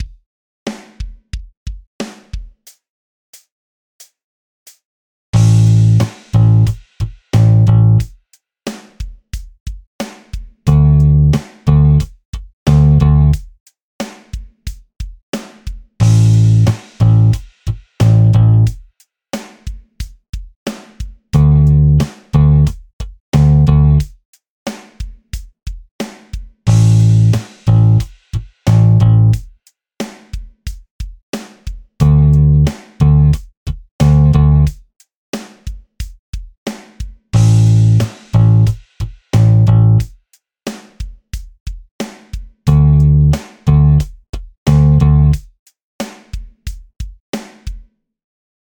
6．開放弦と長い音符／休符を使用したベース練習フレーズ７選！
2．長い休符が含まれる練習フレーズ
長い休符もリズムがズレやすい。